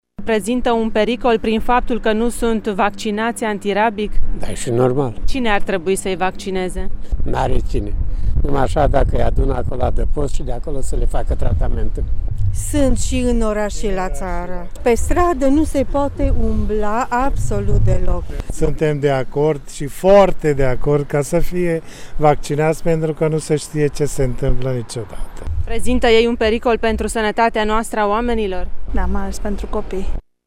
Tîrgumureșenii sunt de părere că patrupedele nevaccinate împotriva turbării, fie că au stăpân sau nu, pun în pericol oamenii: